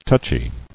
[touch·y]